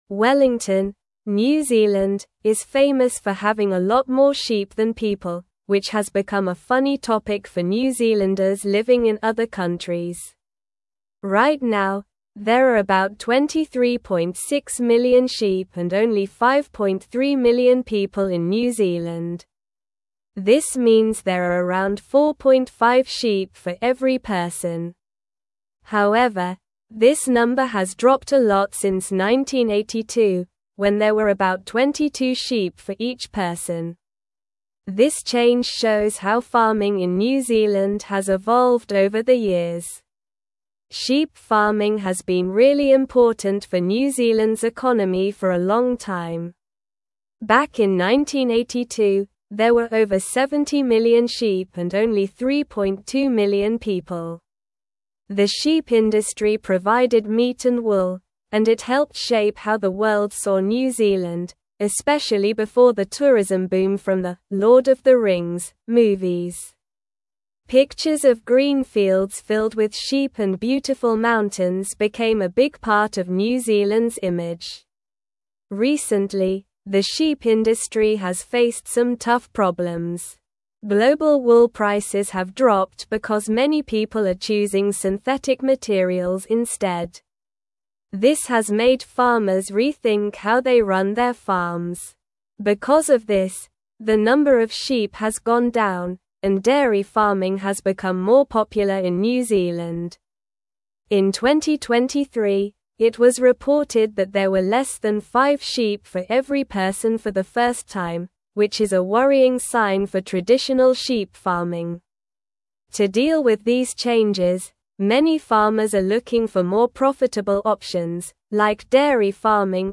Slow
English-Newsroom-Upper-Intermediate-SLOW-Reading-Decline-of-New-Zealands-Sheep-Population-and-Industry.mp3